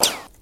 Impacts